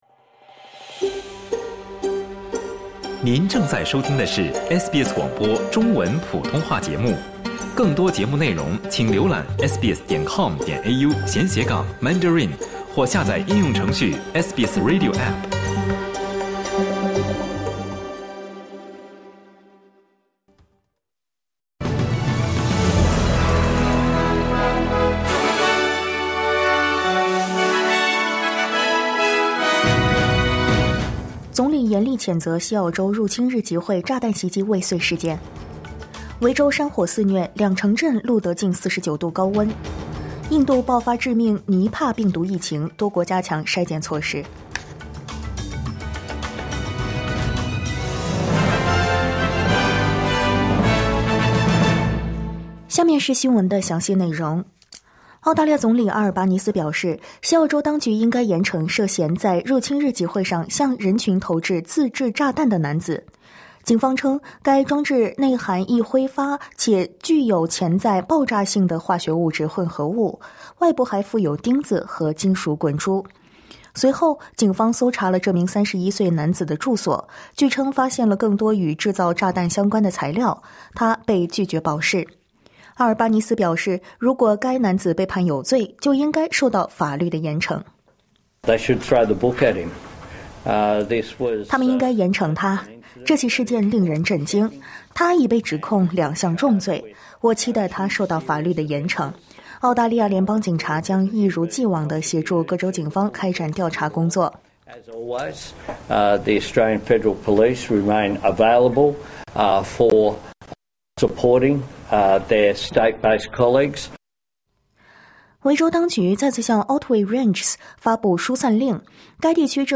【SBS早新闻】印度爆发致命尼帕病毒疫情 多国加强筛检措施